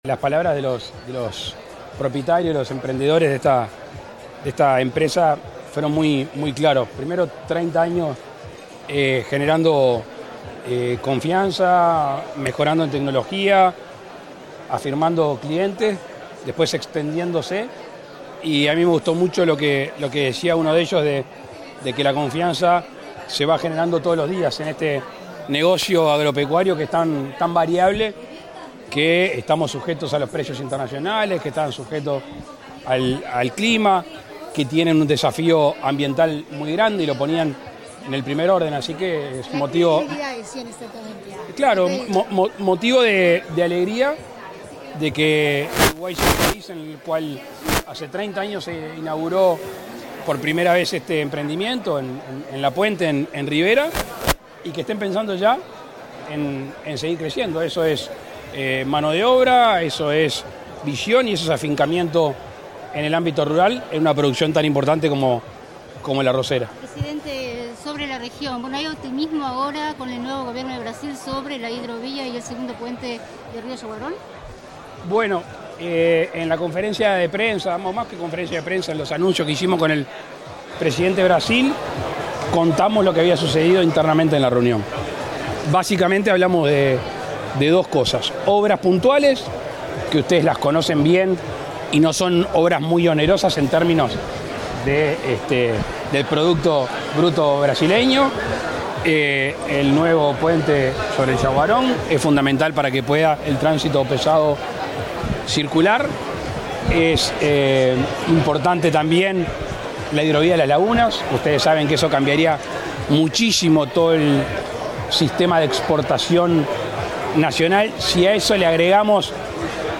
Declaraciones a la prensa del presidente de la República, Luis Lacalle Pou
Tras el evento, el mandatario realizó declaraciones a la prensa.